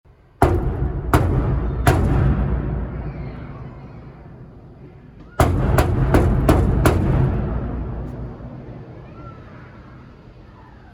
Звуки стуков в дверь
Звук удара по железной двери